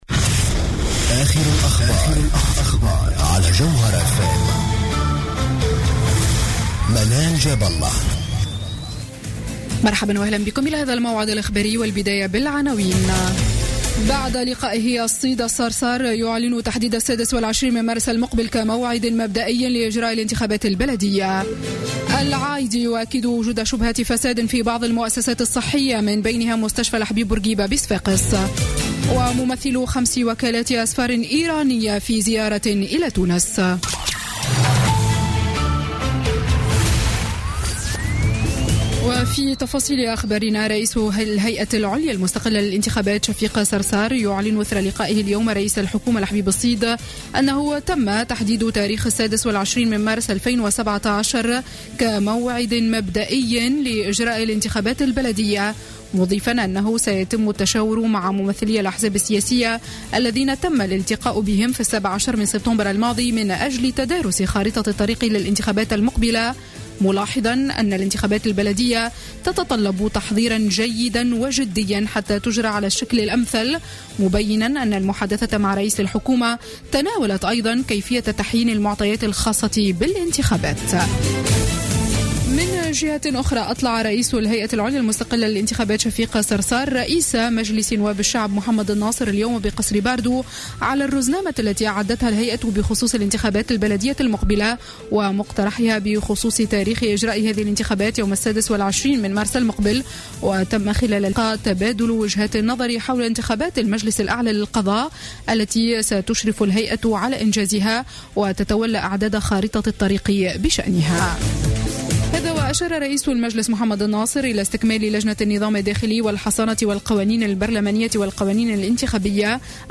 نشرة أخبار السابعة مساء ليوم الجمعة 13 ماي 2016